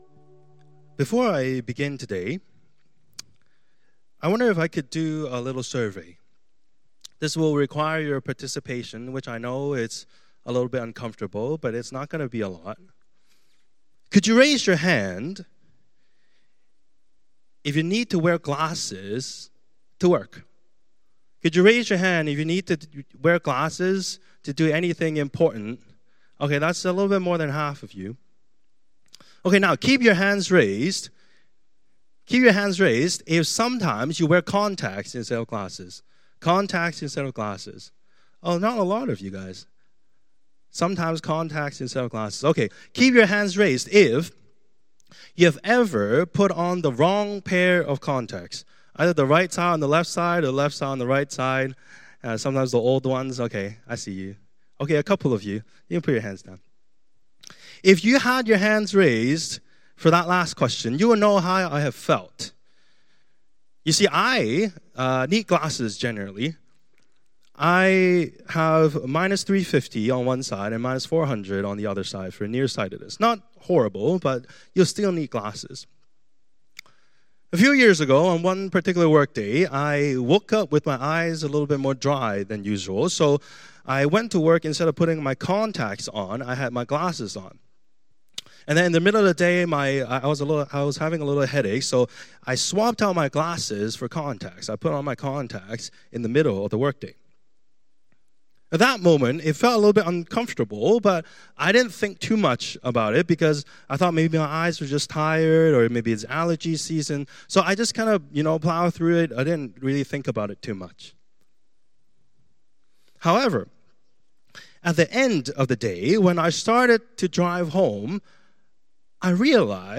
Sermons | Koinonia Evangelical Church | Live Different!